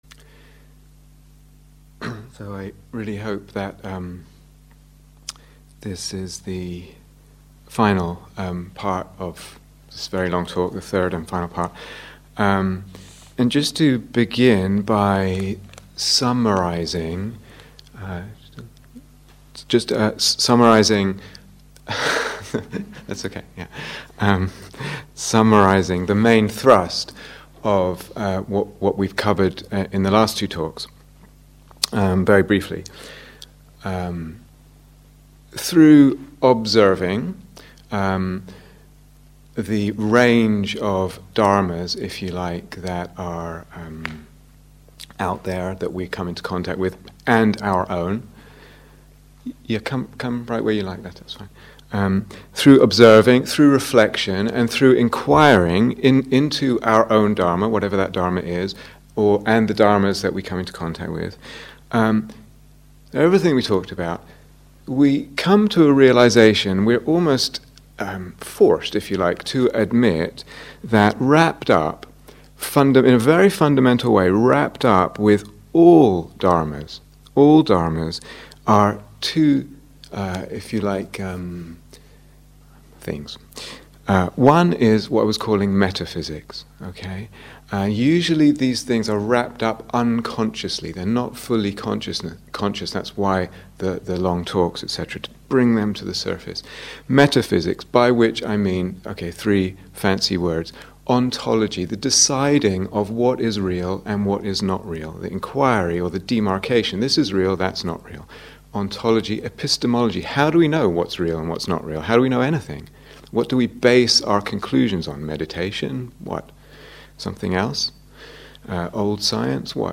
Here is the full retreat on Dharma Seed This talk is the third part of a talk in three parts.